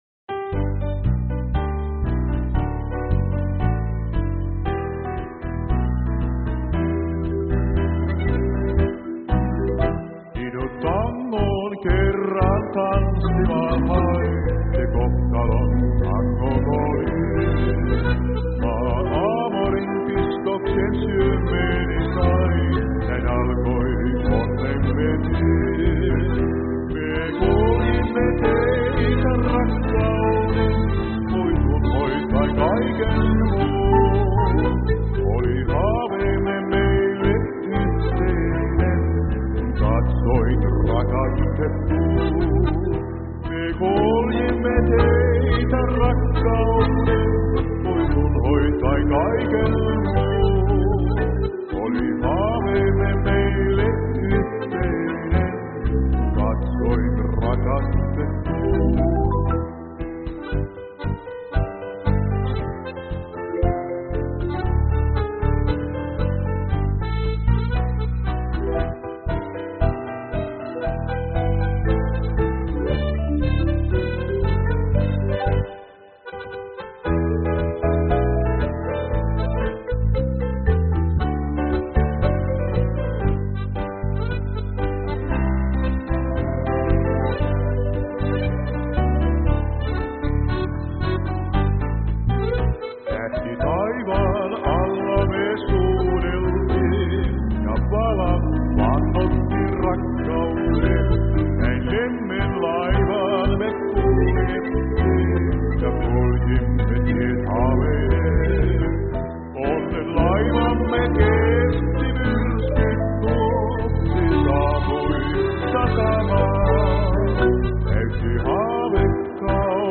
Pianossa